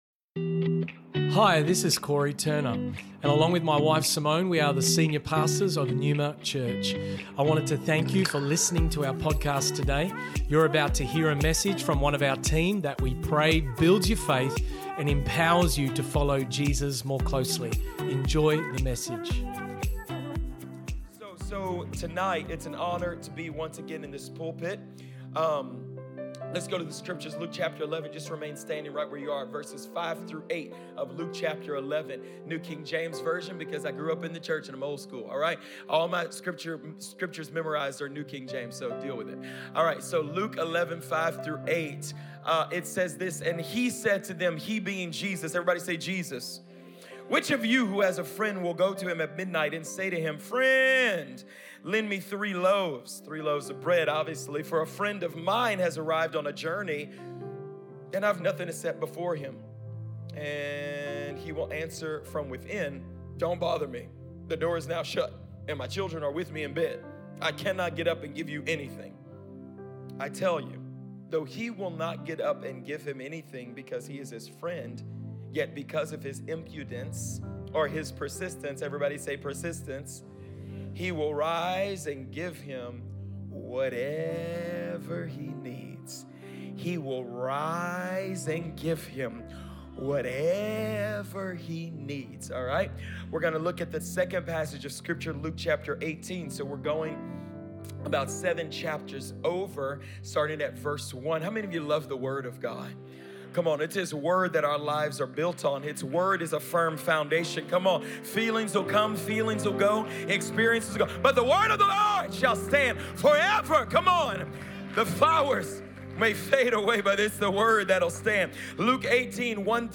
Neuma Church Originally Recorded at the 4PM Revival Service on the 23rd April, 2023&nbsp